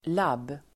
Uttal: [lab:]